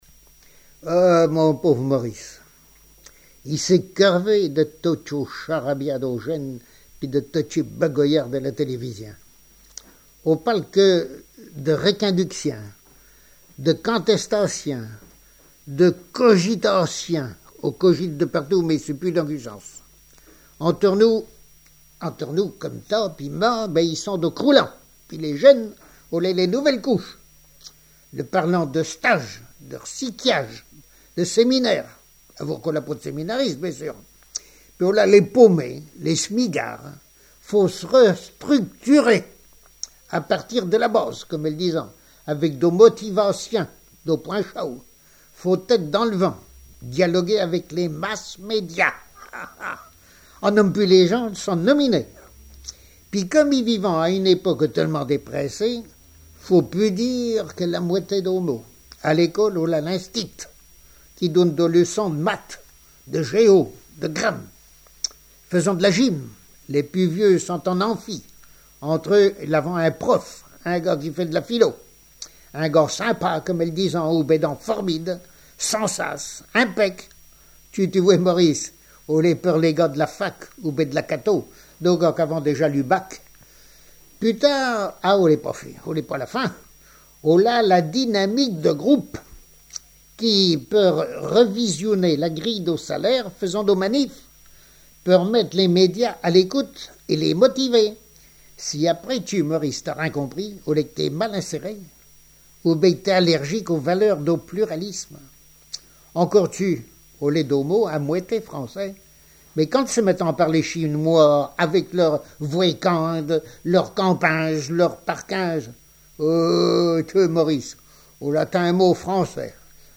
Langue Patois local
Genre sketch
Catégorie Récit